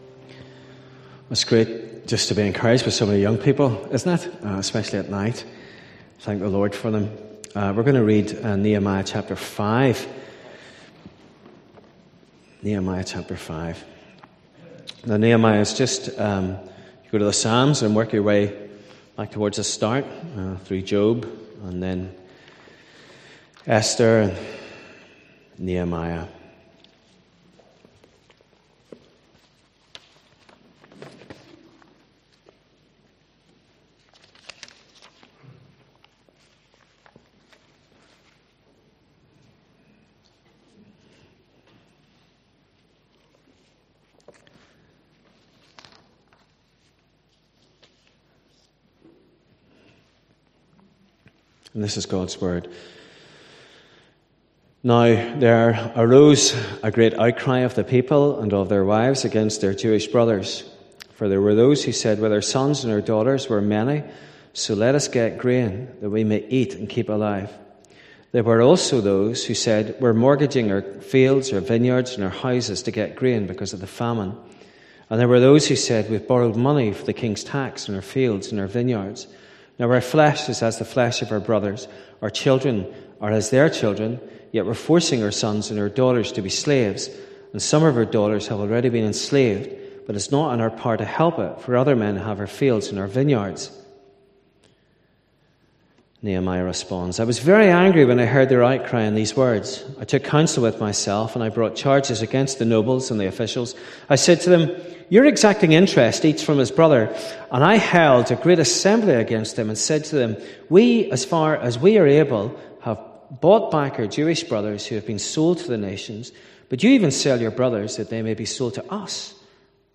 Service Type: pm